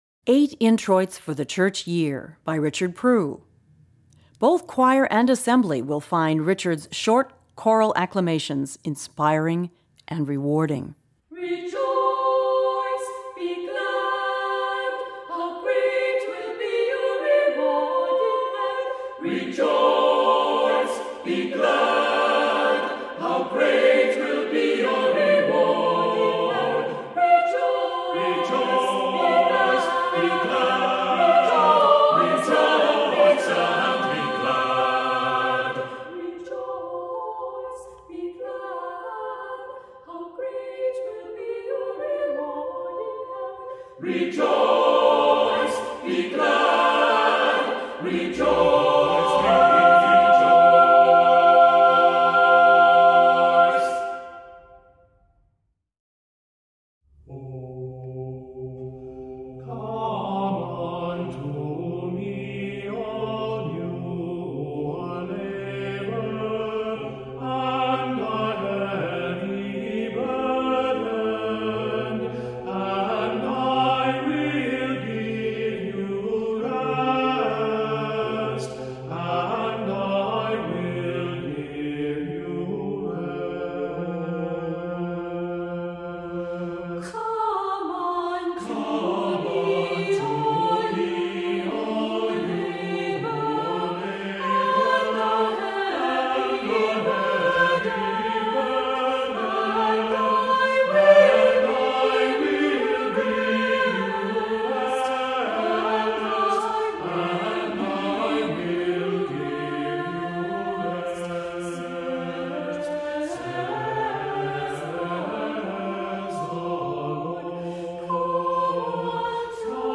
Voicing: SATB,a cappella